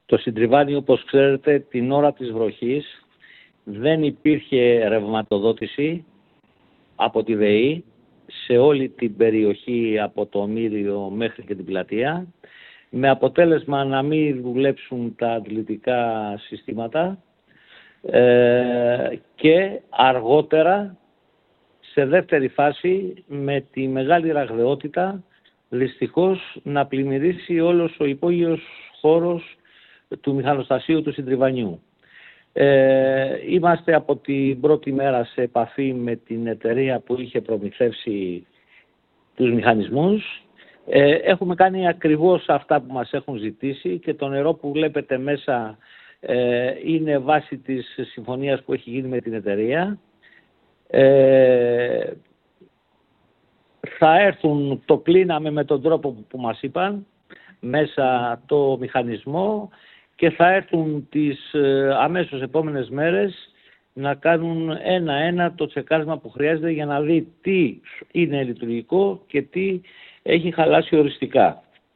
Ο Δήμαρχος Χίου, Γιάννης Μαλαφής, σε δηλώσεις του επιβεβαιώνει τη βαθιά του ανησυχία για την έκταση των ζημιών.
Γιάννης Μαλαφής για σιντριβάνι.mp3